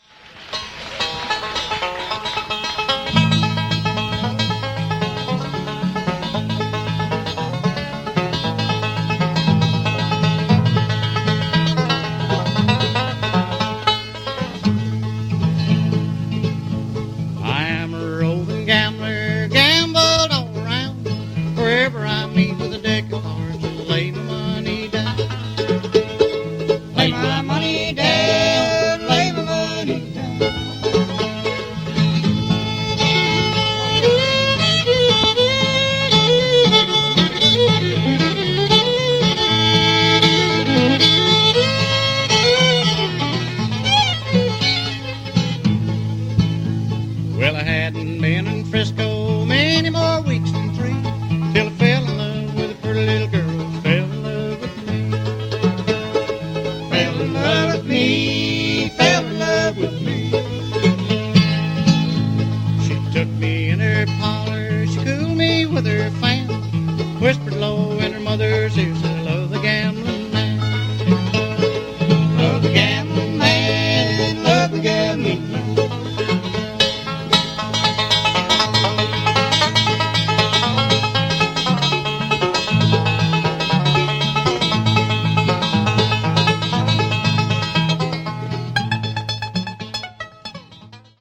Traditional
Listen to Ralph Stanley and band perform "Roving Gambler" (mp3)